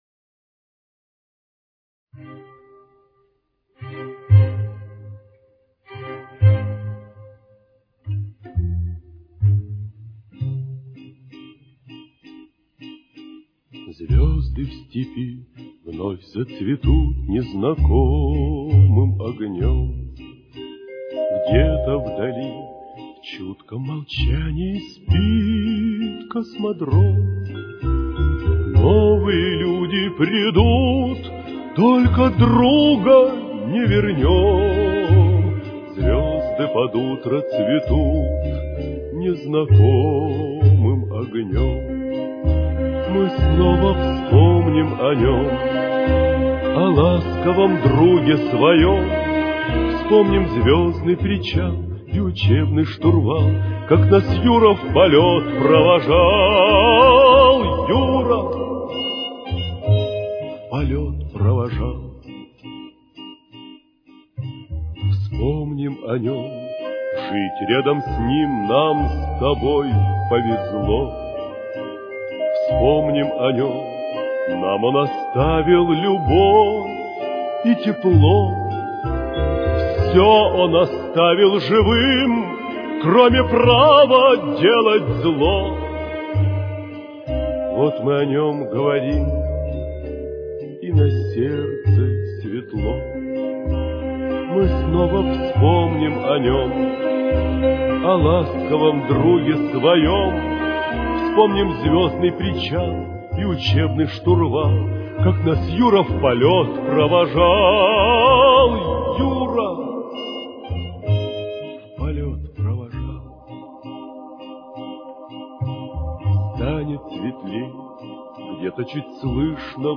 Темп: 66.